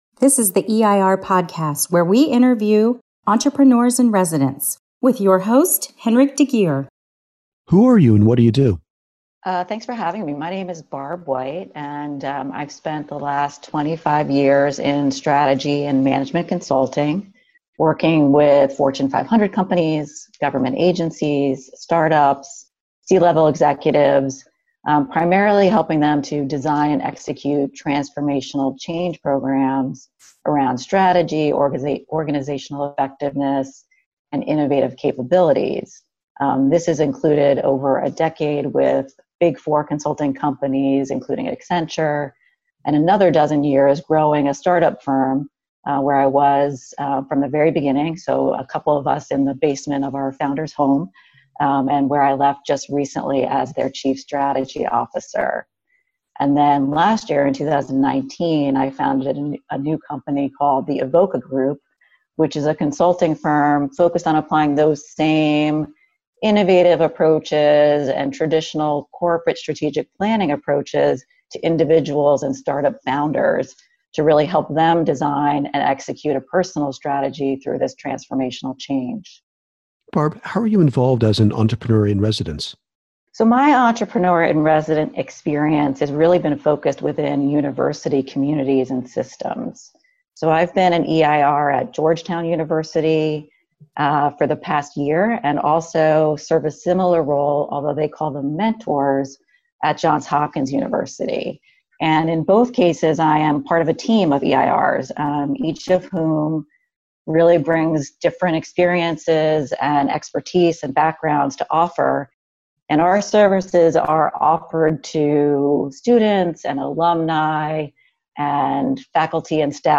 Here is an audio interview with Entrepreneur in Residence